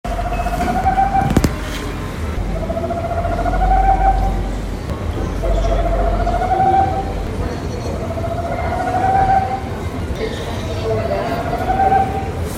Parece uma gargalhada sinistra.
É uma corujinha do mato.
Corujinha-do-mato_amplificado.mp3